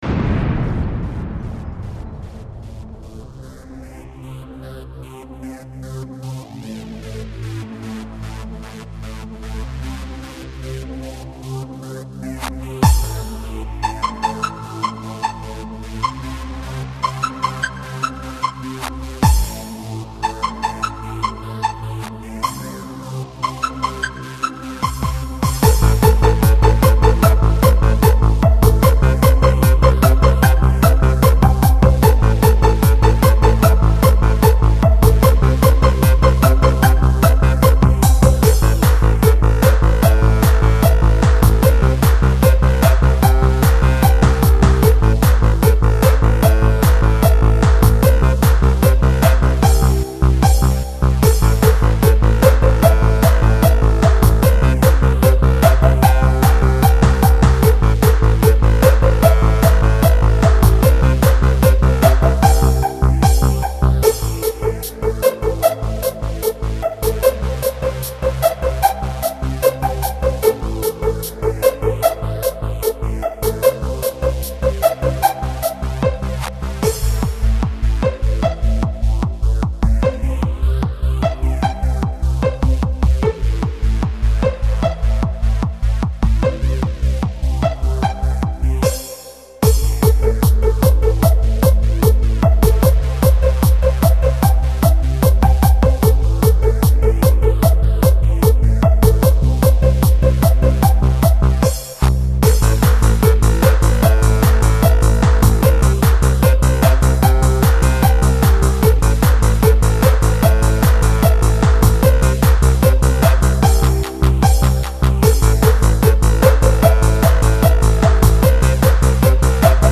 Melodic Trance